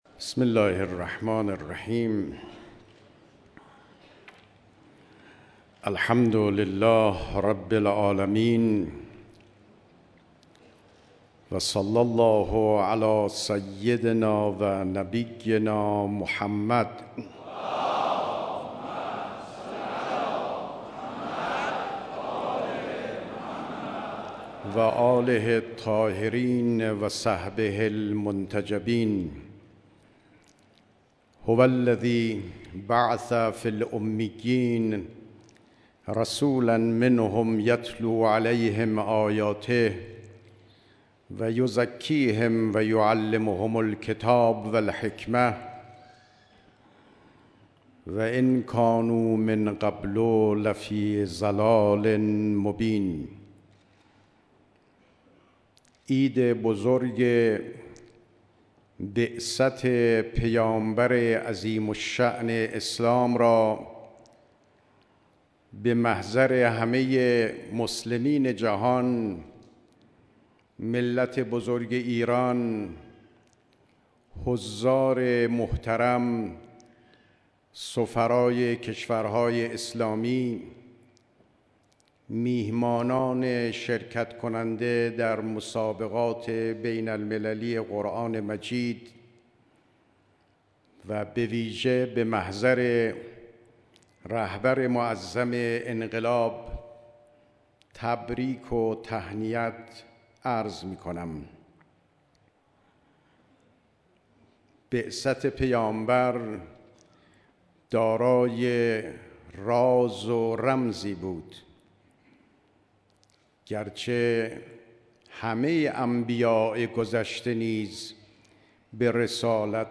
دیدار مسئولان نظام، سفیران کشورهای اسلامی و قشرهای مختلف مردم
سخنرانی ریاست محترم جمهوری جناب آقای روحانی